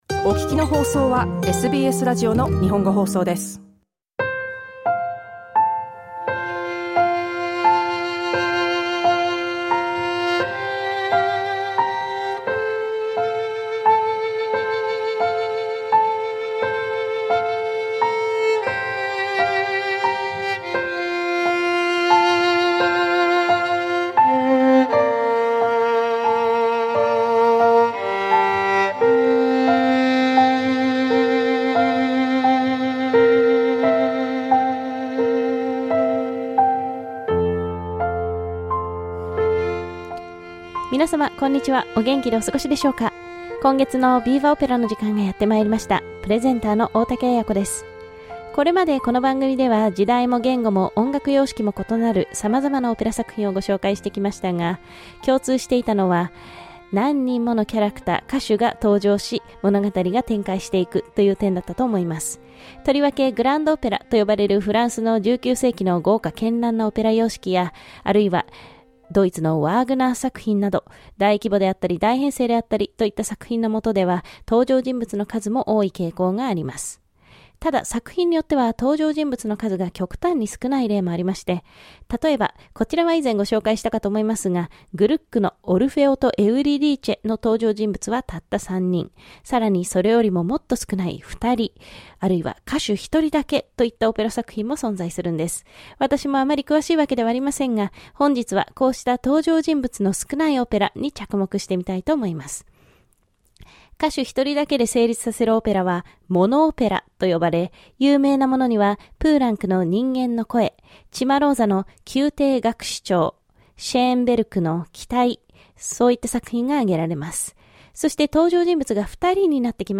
毎月第４・第５木曜日に放送しているオペラ音楽のコーナー「VIVA! Opera」。
ラジオ番組で解説に続いて紹介するのは、20世紀半ばを代表する米国の歌姫エレノア・スティーバーによる歌唱です。